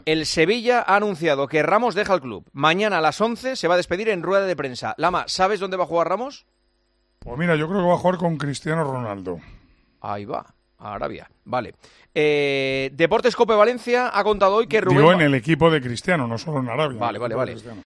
A la pregunta lanzada por Juanma Castaño: "¿Lama, sabes dónde va Sergio Ramos?", el comentarista del Real Madrid y de la Selección en Tiempo de Juego, ha dejado una respuesta que no dejará indiferente a muchos aficionados.